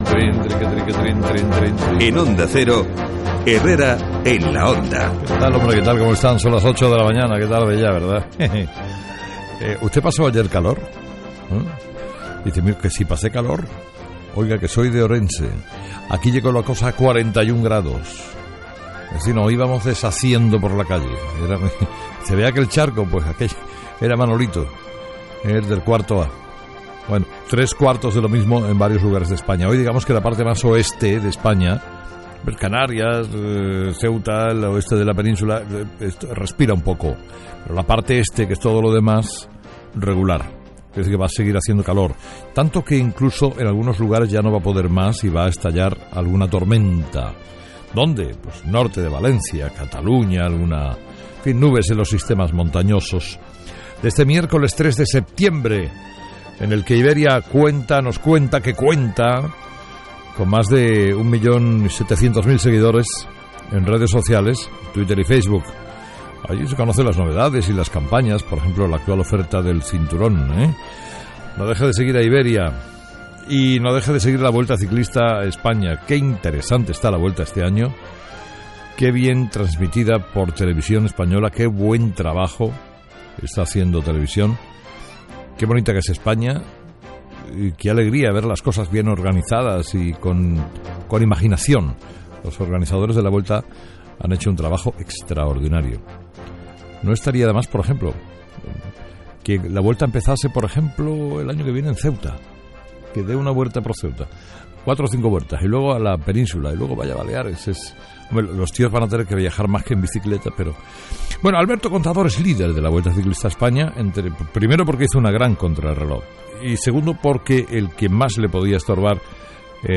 Carlos Herrera comenta en su editorial el tema que acapara la atención: la comparecencia de Cristóbal Montoro en el Congreso para hablar de fraude fiscal y concretamente del Caso Pujol.